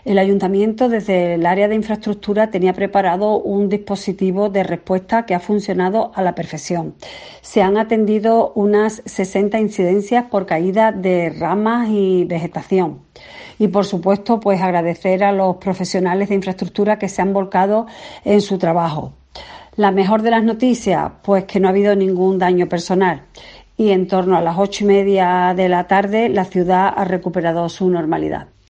Mariló Ponce, concejal de Infraestructuras del Ayuntamiento de Huelva